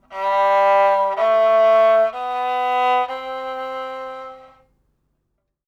Sounds on Kamancheh strings on the assumption of basing the most prevalent tune (TUNE 1 in these instruments) are like this:
4th string in 1st position: